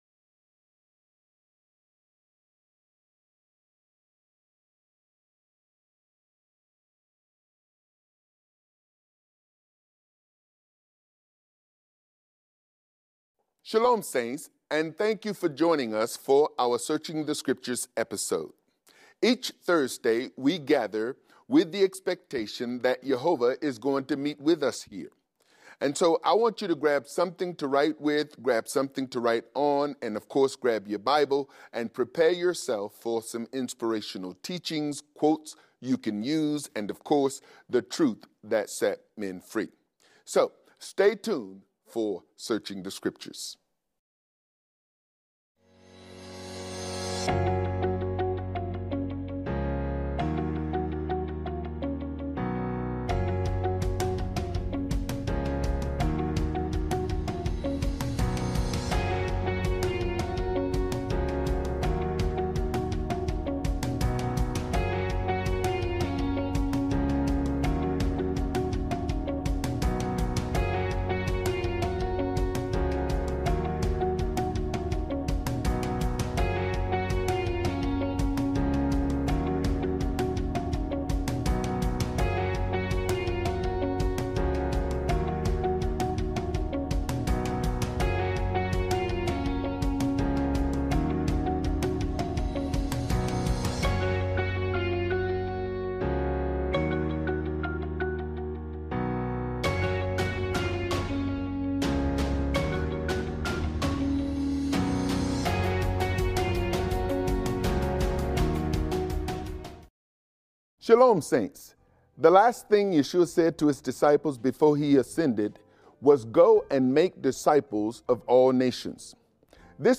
A Biblical Teaching